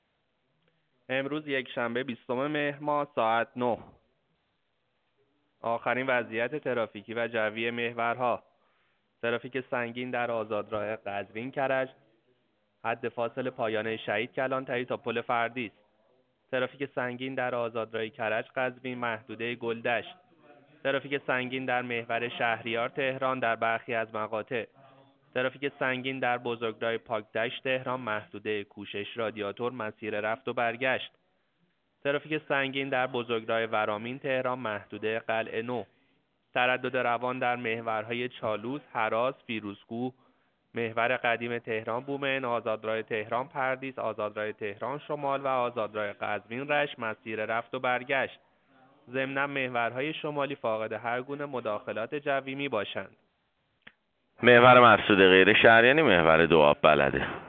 گزارش رادیو اینترنتی از آخرین وضعیت ترافیکی جاده‌ها ساعت ۹ بیستم مهر؛